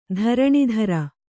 ♪ dharaṇidhara